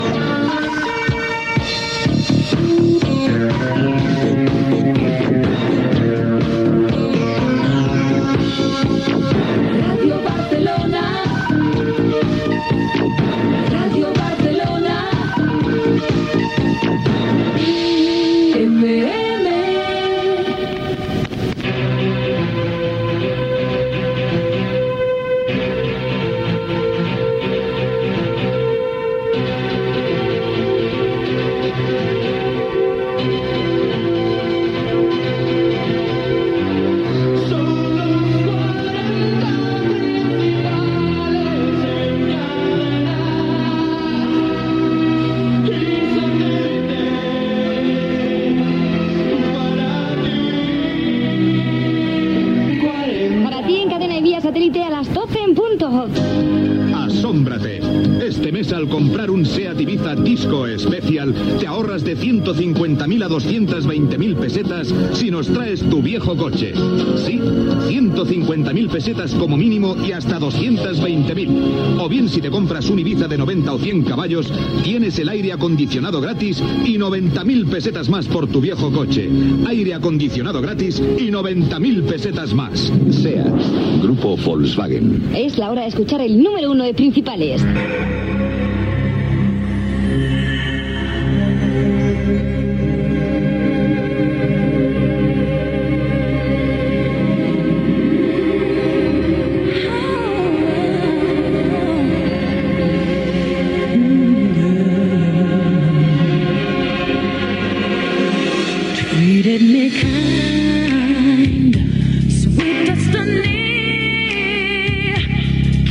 Indicatiu emissora, jingle de la Cadena 40 Principales, hora, publicitat i presentació del tema musical número 1 de Principales
Musical